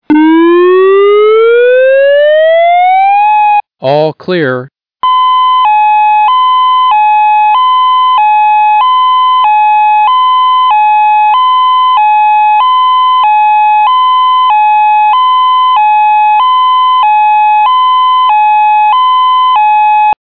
The all clear tone will sound like this:
All-Clear-MP3